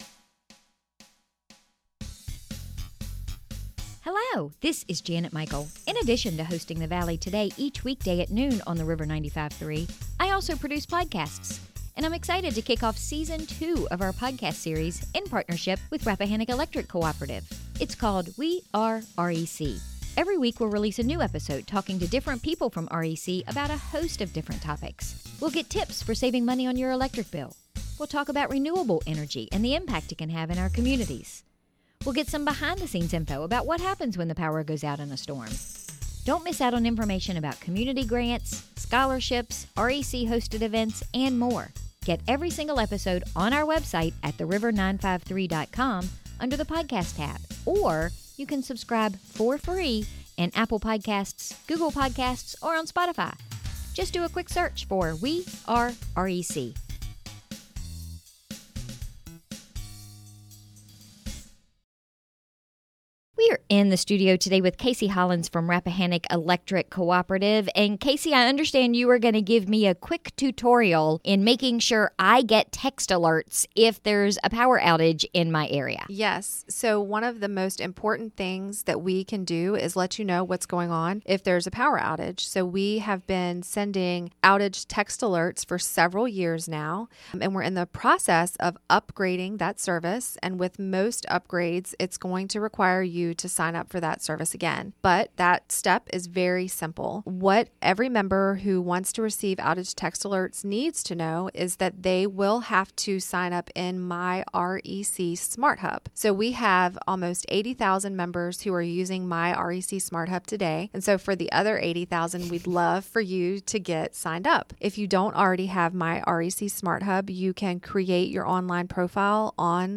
We were in the studio